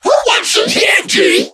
mech_mike_start_vo_03.ogg